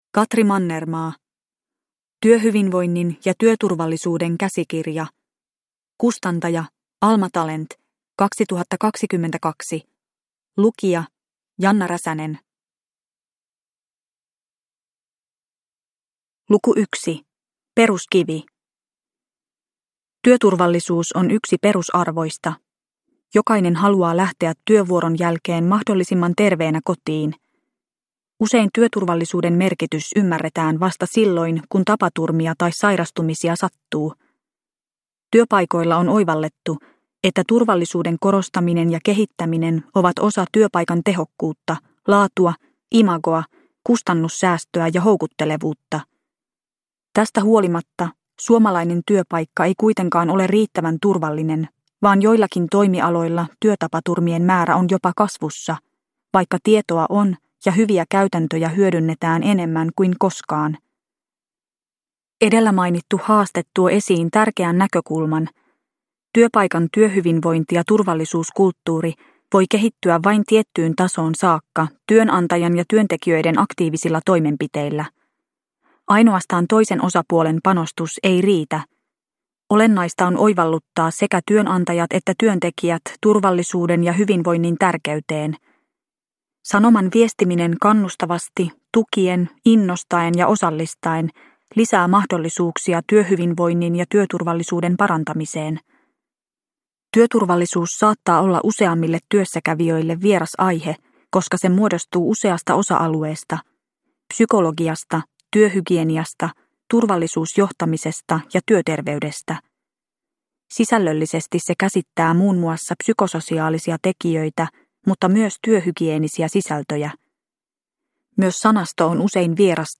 Työturvallisuuden ja työhyvinvoinnin käsikirja – Ljudbok – Laddas ner